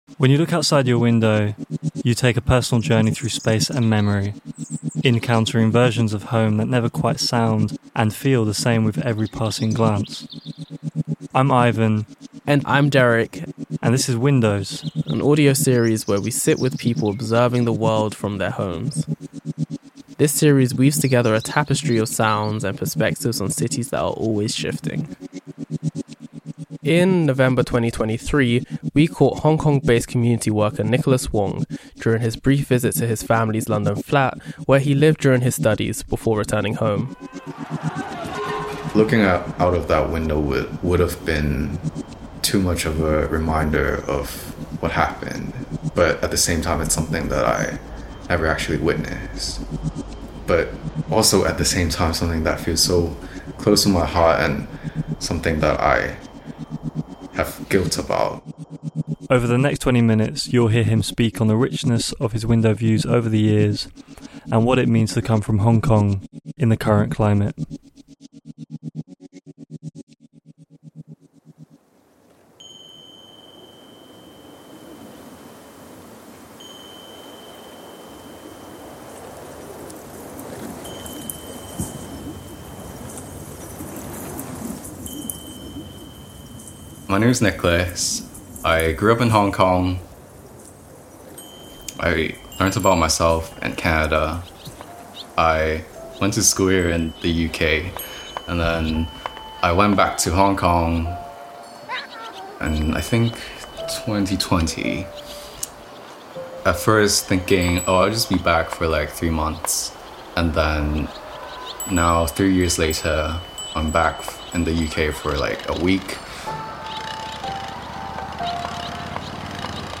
Along with soundscapes of migrating swallows, tree-top monkeys and sunlit curtains
Listening with headphones recommended